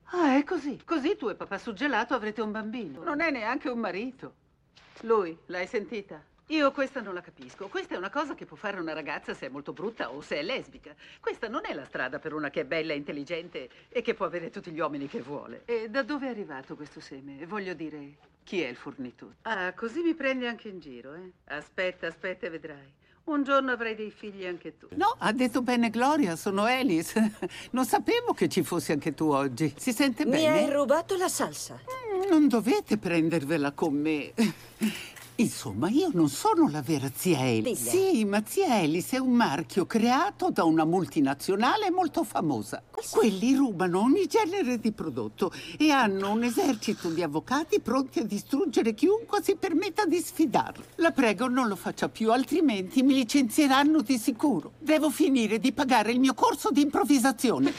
film "Senti chi parla", in cui doppia Olympia Dukakis, e nel telefilm "Modern Family", in cui doppia June Squibb.